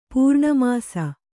♪ pūrṇa māsa